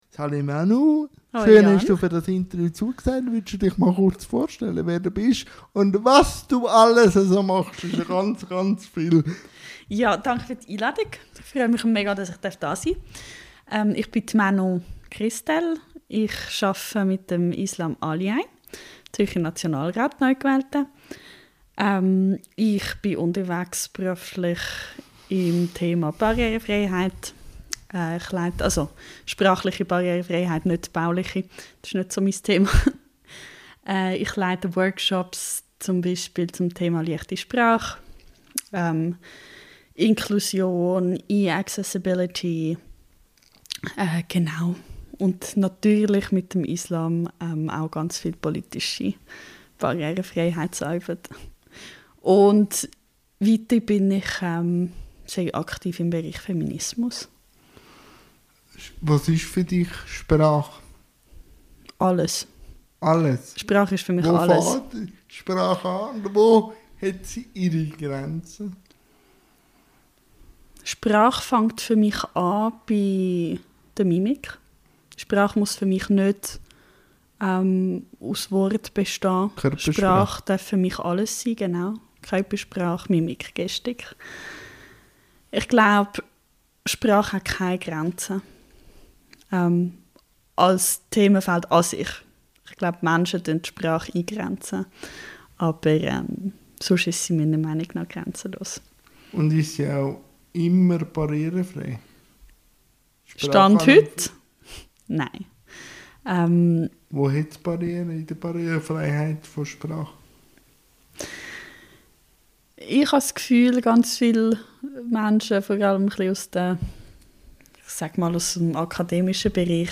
INTERVIEW-THEMEN